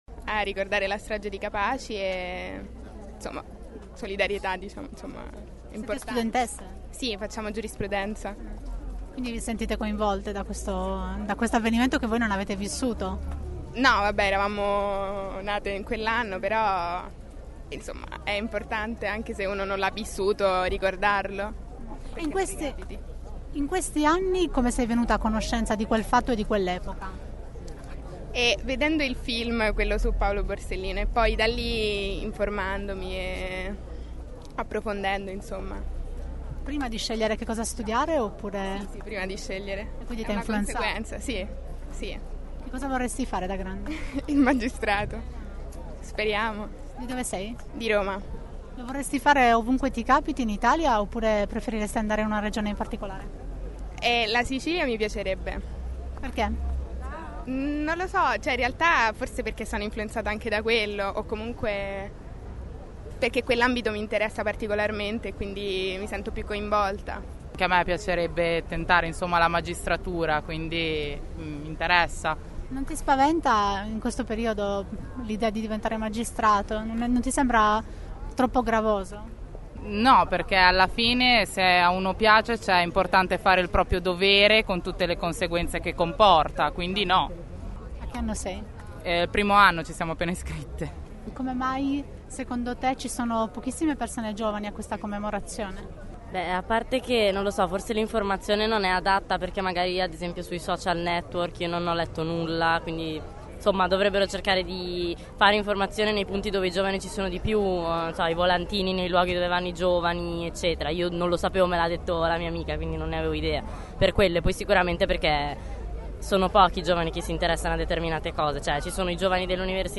Lo ha detto durante la commemorazione davanti all’albero della memoria, facendo riferimento alle minacce al giornalista Giovanni Tizian, ai pedinamenti e messaggi di ‘attenzione’ rivolti ad alcuni magistrati, alla “presenza di ‘compaesani’ degli imputati persino ad un processo di Corte d’Appello qui a Bologna dove si giudicava di fatti di estorsione aggravata dal metodo mafioso e dove ciò che interessava era evidentemente il comportamento di un nuovo collaboratore di giustizia, da tenere sotto controllo”.